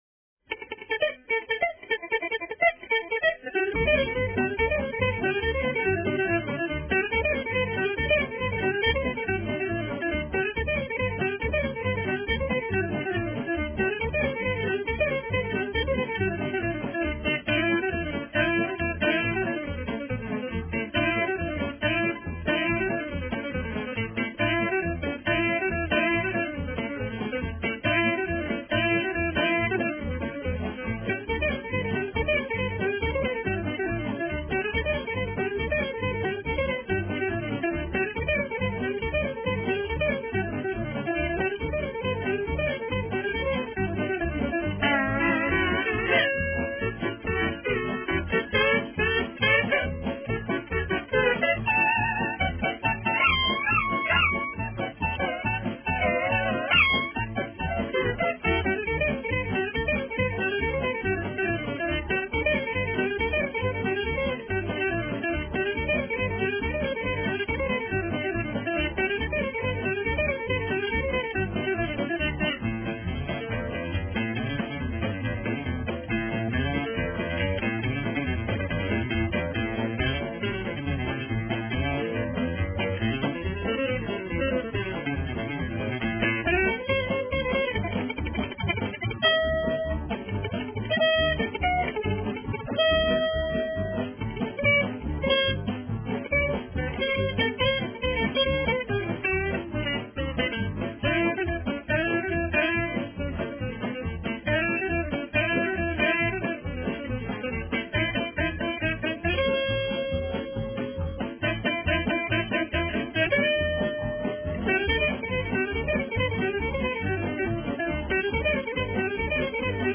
country
steel player